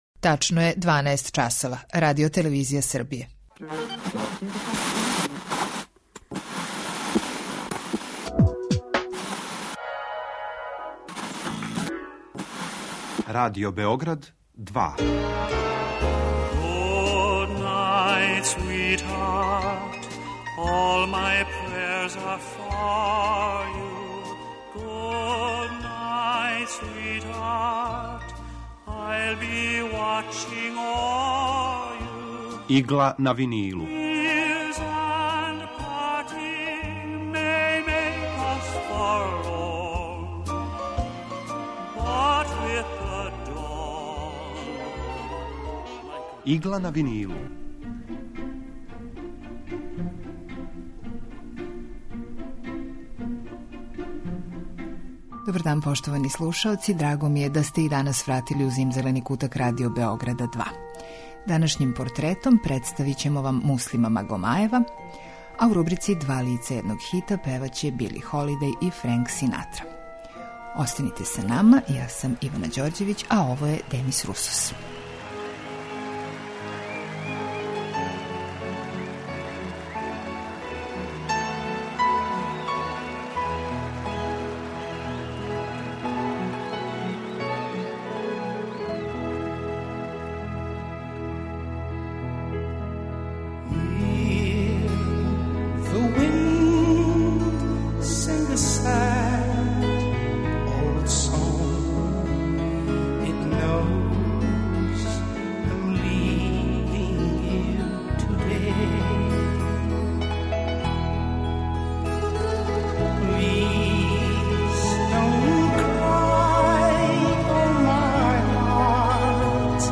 Емисија евергрин музике